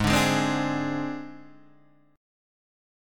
G# Major Flat 5th